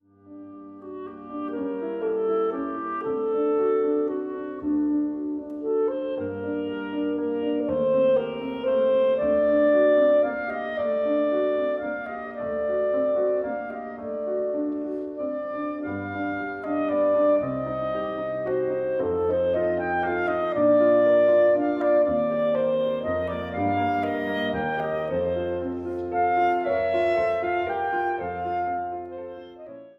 Klarinette
Bassetthorn und Klarinette
Klavier